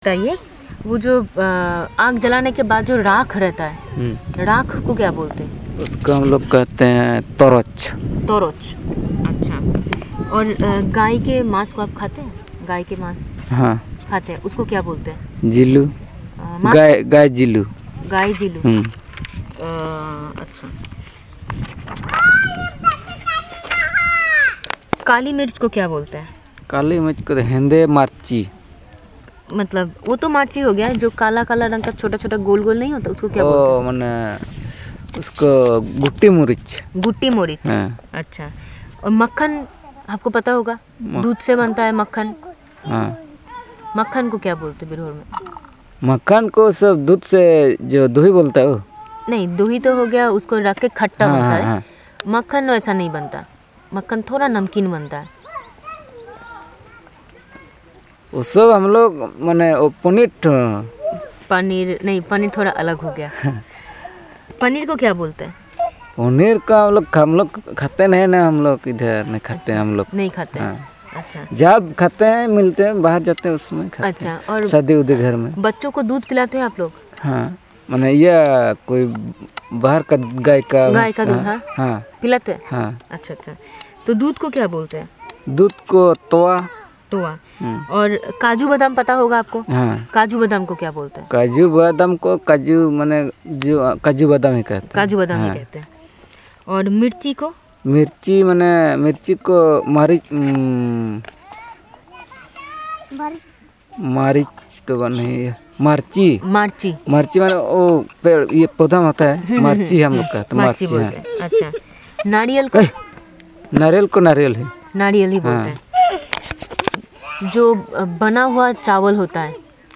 Elicitation of words related to food items